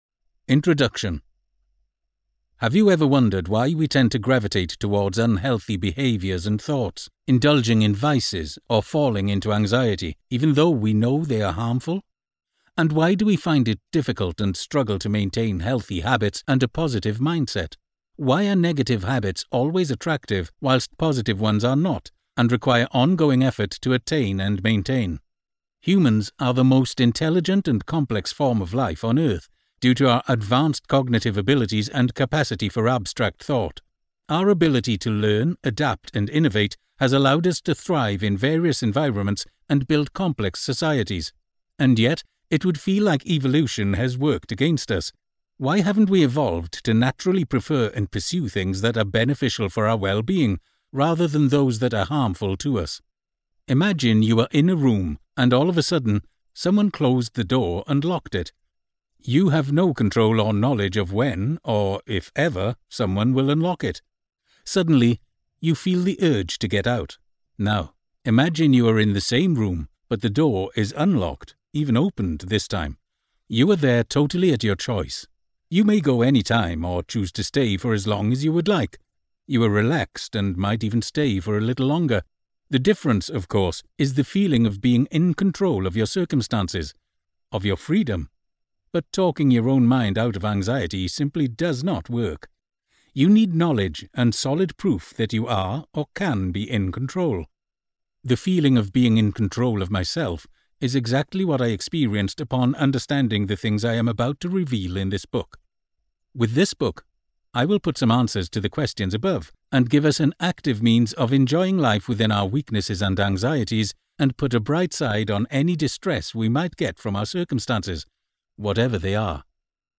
Buy Audiobook Now